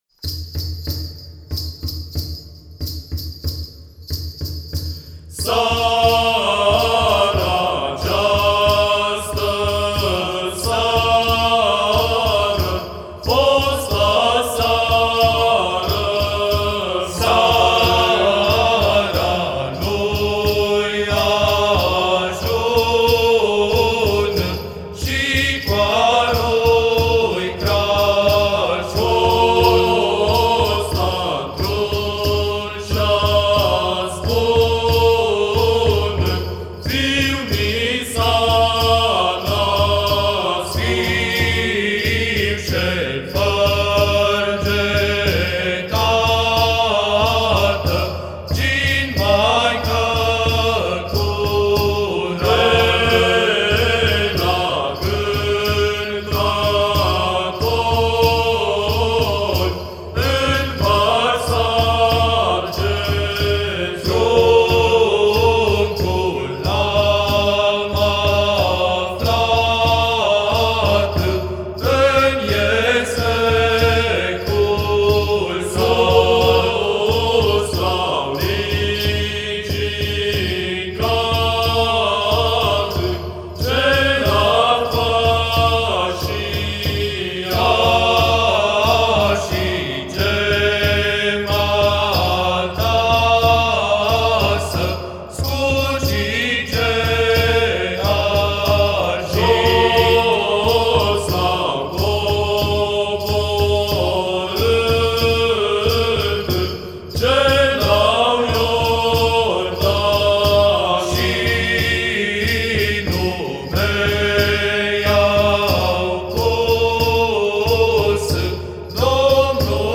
Corul Kinonia al Episcopiei Severinului şi Strehaiei
Colinde - Cântece de stea din Mehedinţi
Colinde
Cântecele de stea înregistrate pe acest Compact Disc sunt culese din satul Păuneşti, comuna Godeanu, judeţul Mehedinţi.